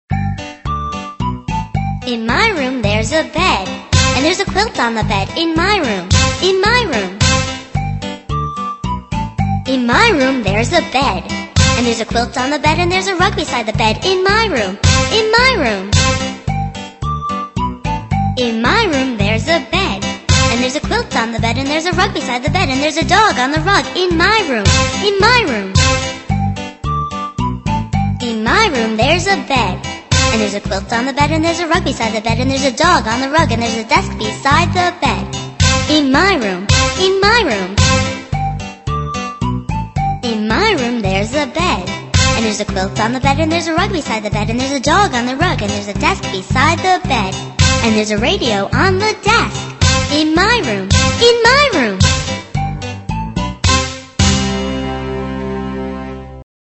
在线英语听力室英语儿歌274首 第95期:In my room的听力文件下载,收录了274首发音地道纯正，音乐节奏活泼动人的英文儿歌，从小培养对英语的爱好，为以后萌娃学习更多的英语知识，打下坚实的基础。